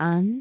speech
syllable
pronunciation
aan5.wav